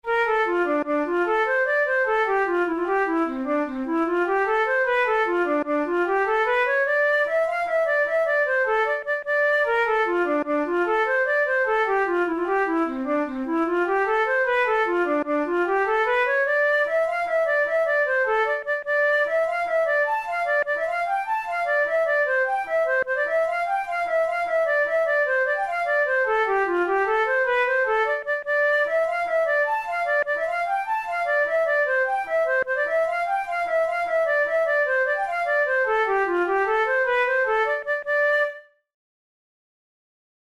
InstrumentationFlute solo
KeyD minor
Time signature6/8
Tempo100 BPM
Jigs, Traditional/Folk
Traditional Irish jig